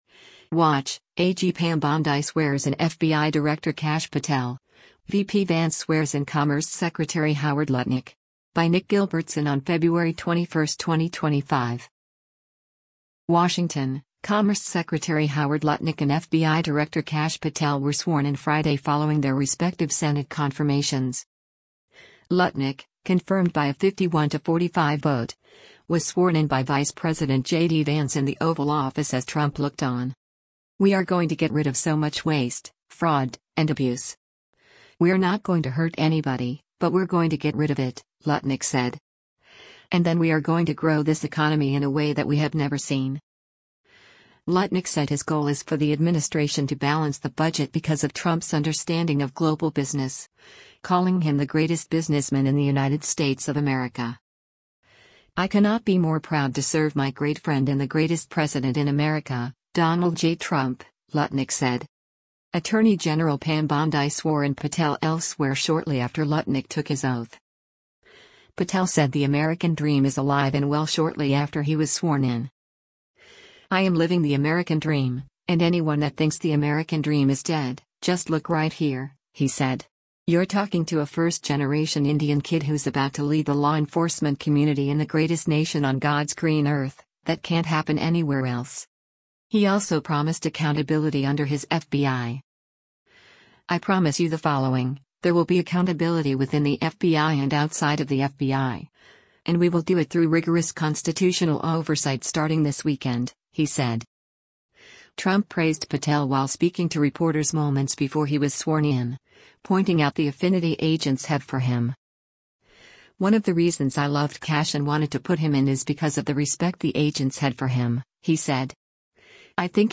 Watch: AG Pam Bondi Swears in FBI Director Kash Patel, VP Vance Swears in Commerce Secretary Howard Lutnick
Lutnick, confirmed by a 51-45 vote, was sworn in by Vice President JD Vance in the Oval Office as Trump looked on.